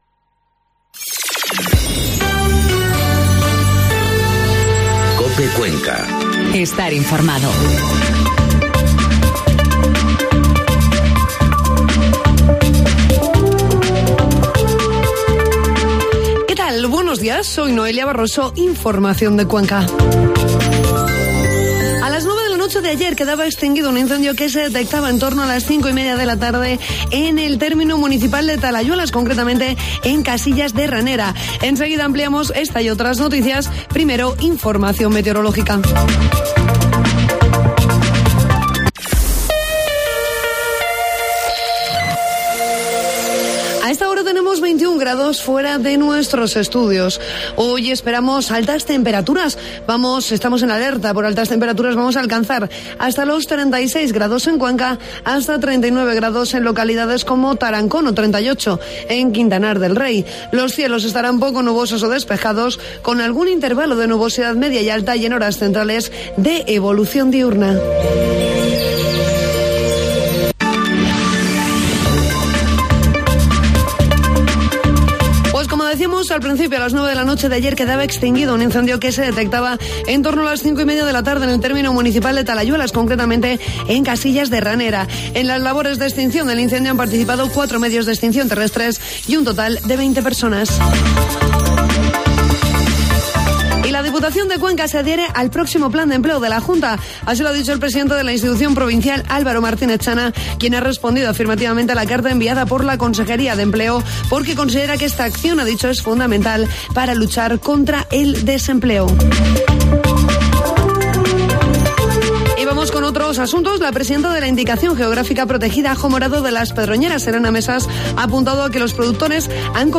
Informativo matinal COPE Cuenca 24 de julio